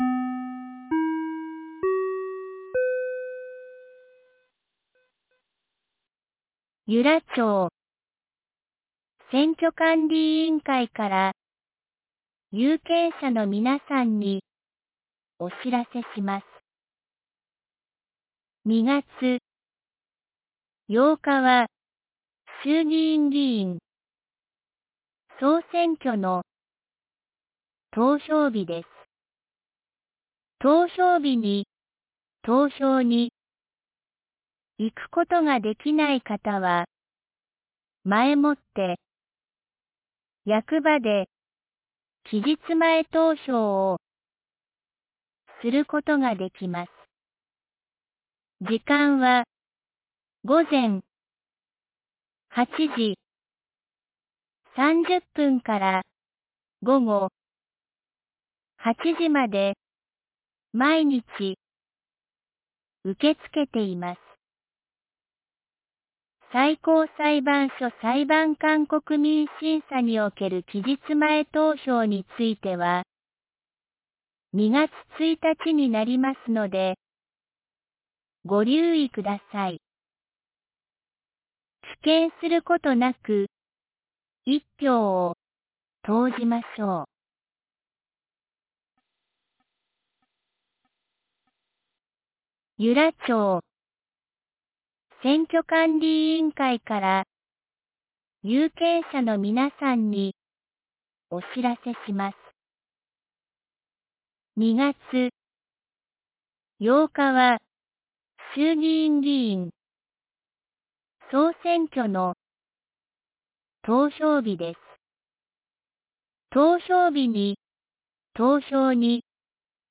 2026年01月30日 12時23分に、由良町から全地区へ放送がありました。